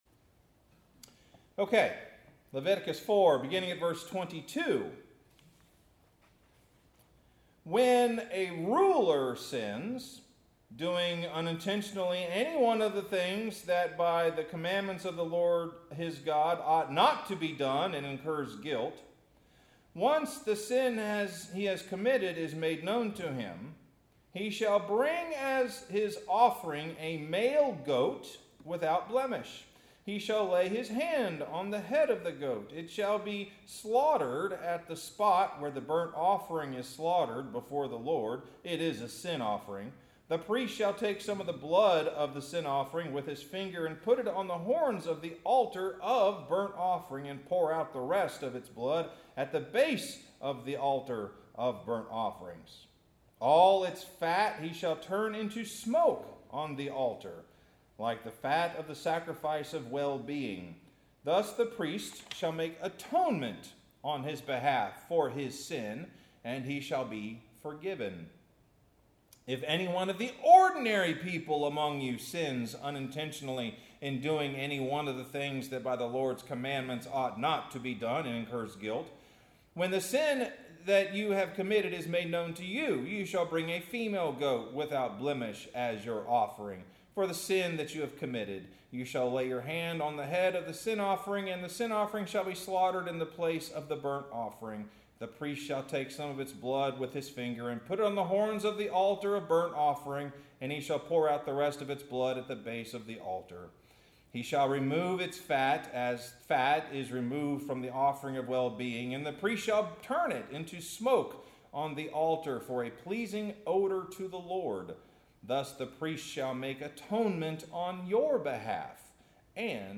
Sermon – Forgive Me Not (Only Once)